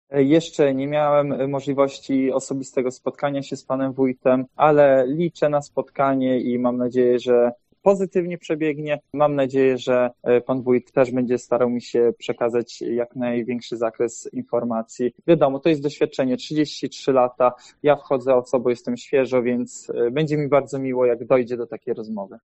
Pan Wójt Józef Zajkowski ma ogromne doświadczenie, zatem liczę na wskazówki-mówił wygrany Radosław Choiński: